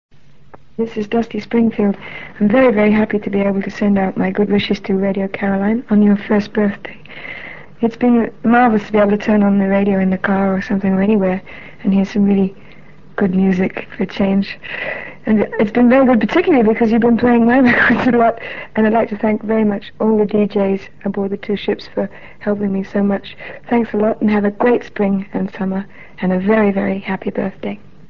A number of pop stars recorded messages, thanking Radio Caroline for its support and wishing the station a happy first birthday.